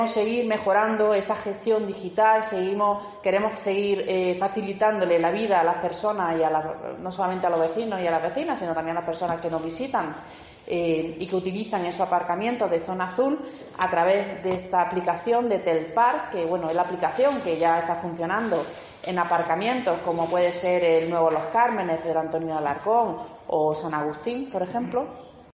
Raquel Ruz, concejal de movilidad